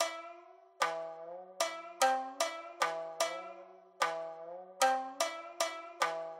На этой странице собраны звуки сямисэна — уникального трёхструнного инструмента, популярного в японской музыке.
Звучание японской арфы самисэн